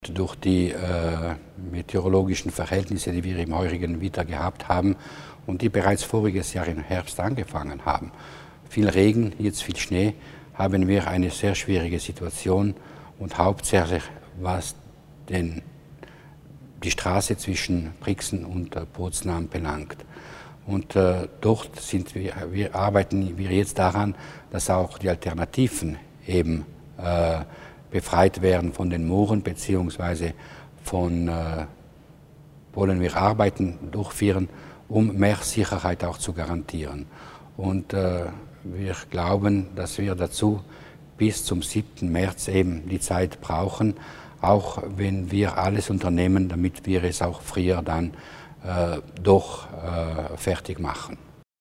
Landesrat Schuler weist auf die Eingriffe nach den ergiebigen Schneefällen hin